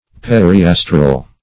Search Result for " periastral" : The Collaborative International Dictionary of English v.0.48: Periastral \Per`i*as"tral\, a. Among or around the stars.